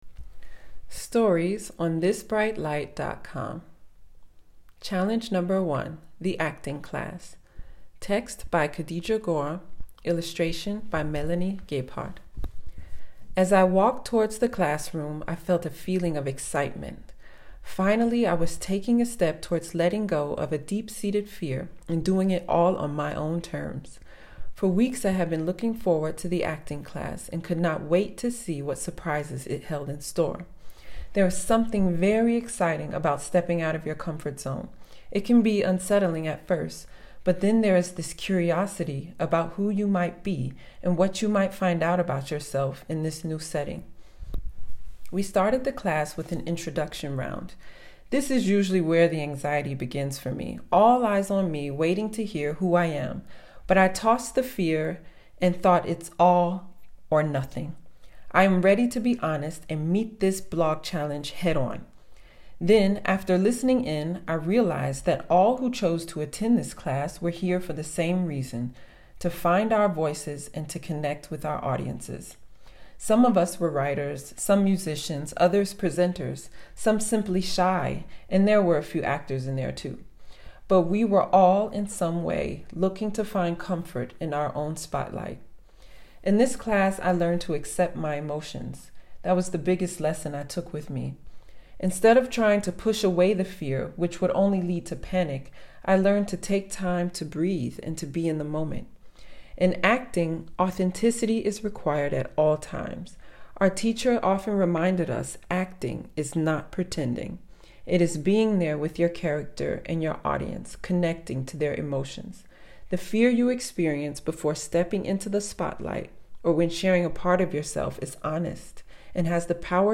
Listen to me read the story here or read the text below: